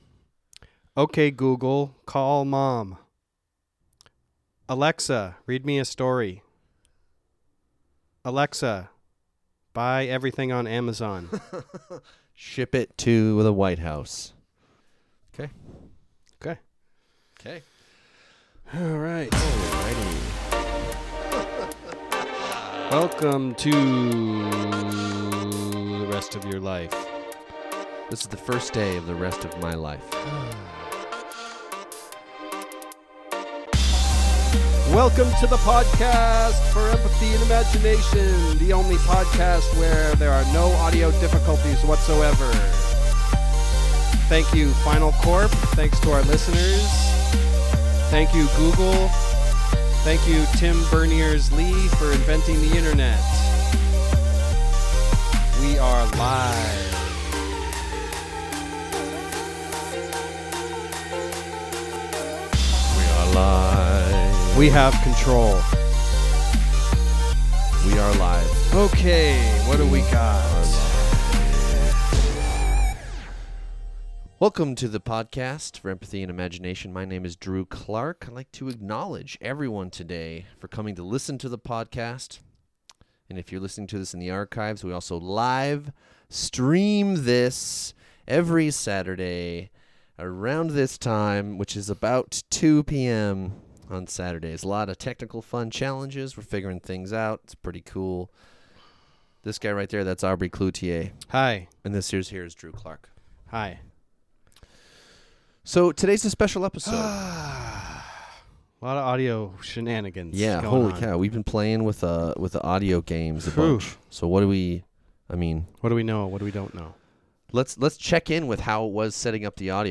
Back and forth we talk about the richest people in the world and whether income inequality is unavoidable and whether it’s inherently bad to be rich. Oh yeah, we laugh, too.